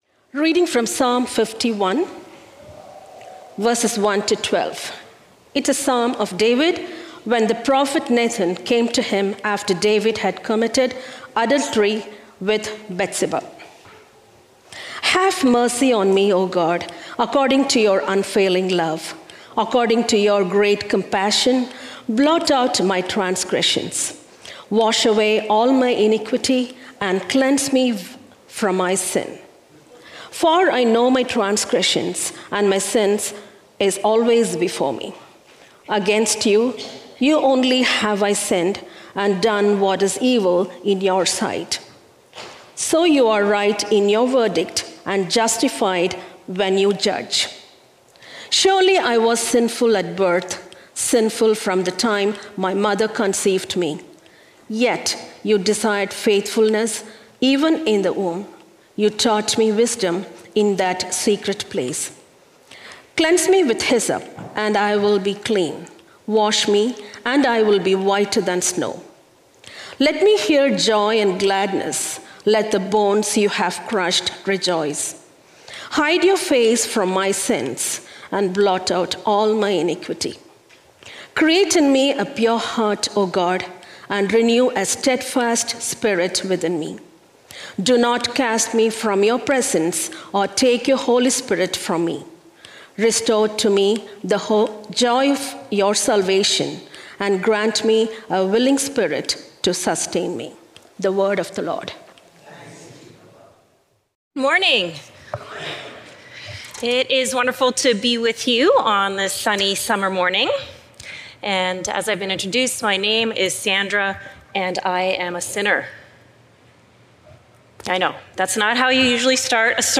Trinity Streetsville - Songs of Confession | Summer Playlist | Trinity Sermons